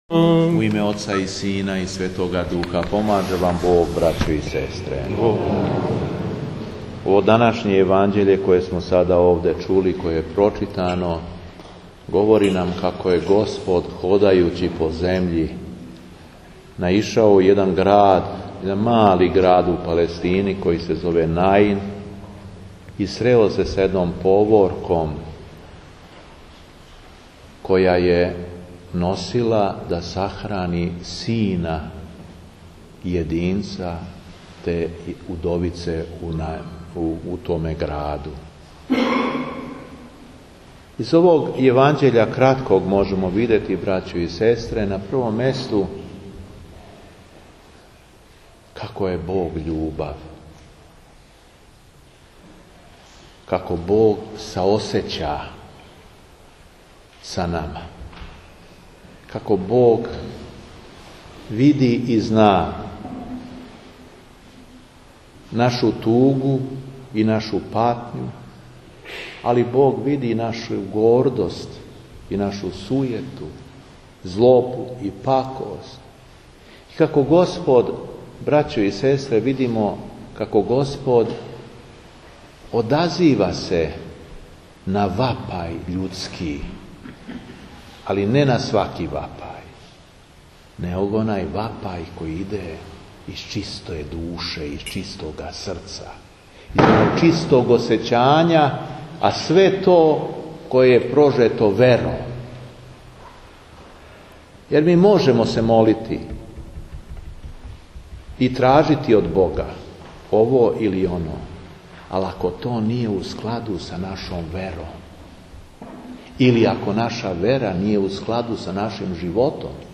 У недељу 18. октобра 2015. године, Његово Преосвештенство епископ шумадијски Господин Јован, служио је у храму Светог великомученика Димитрија у Леско...
Беседа епископа шумадијског Г. Јована